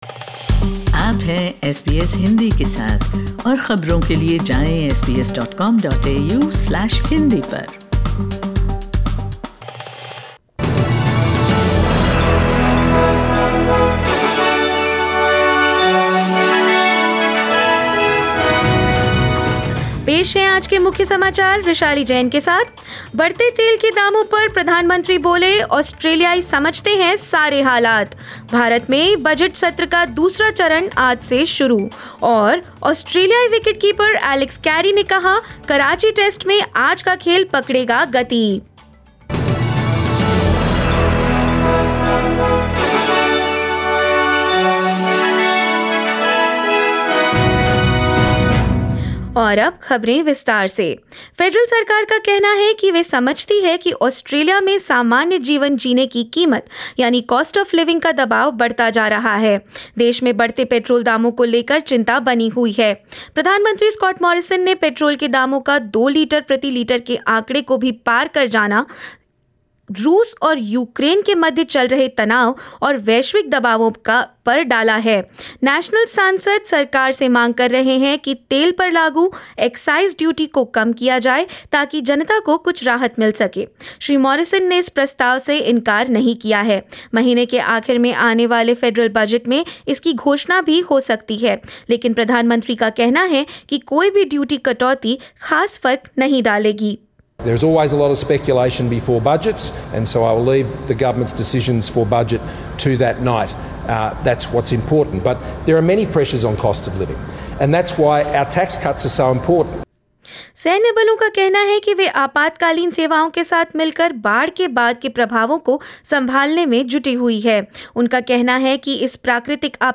In this latest SBS Hindi bulletin: Prime Minister Scott Morrison flags tax relief in the forthcoming budget as cost of living rises in the country; Ukraine requests NATO to implement a 'no-fly zone' over it repeatedly; Australian squad promises a pacey day on the third day of second Karachi Test match and more news.